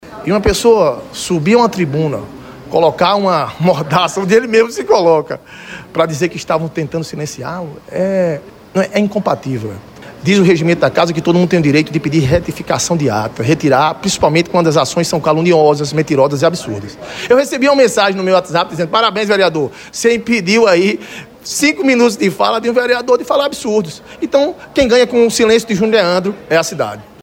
Vereador protesta contra ‘censura’ ao seu discurso e se amordaça no plenário da Câmara da Capital
O vereador Junio Leandro (PDT) protagonizou um protesto inusitado na tribuna da Câmara Municipal de João Pessoa (CMJP).